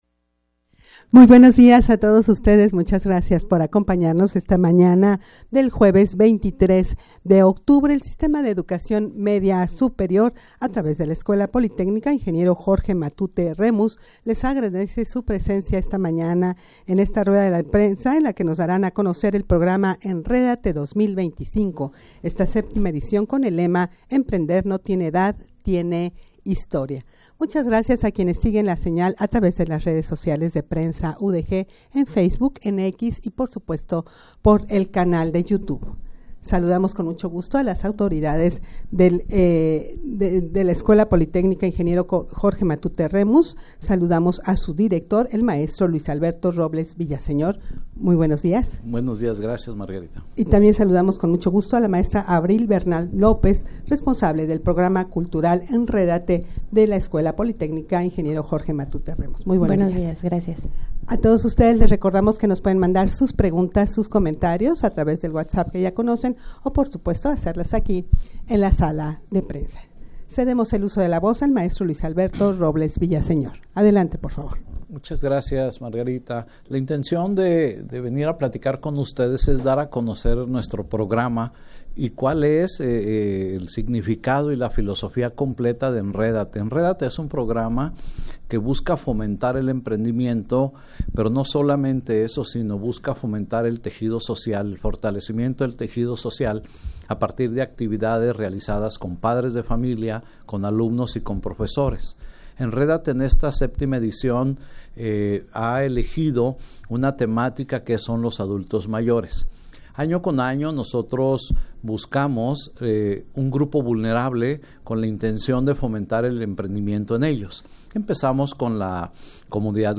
Audio de la Rueda de Prensa
rueda-de-prensa-para-dar-a-conocer-el-programa-enredate-2025.mp3